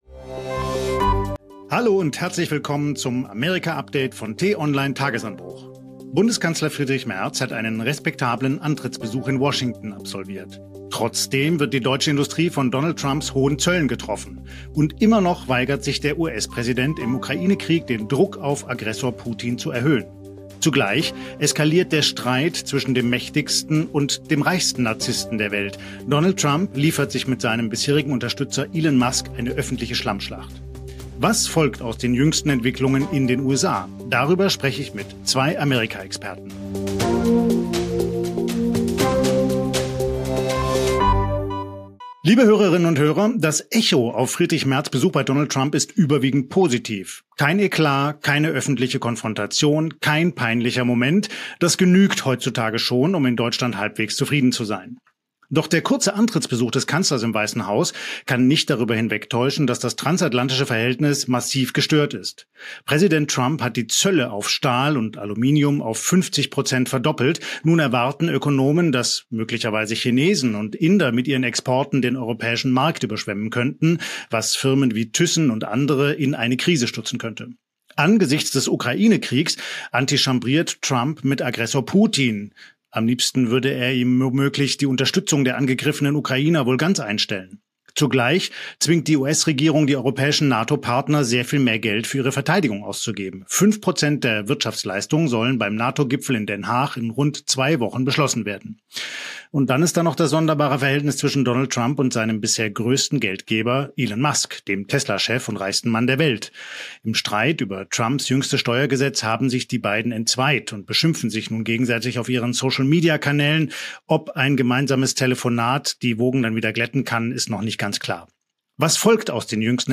Den „Tagesanbruch“-Podcast gibt es immer montags bis samstags gegen 6 Uhr zum Start in den Tag – am Wochenende mit einer längeren Diskussion.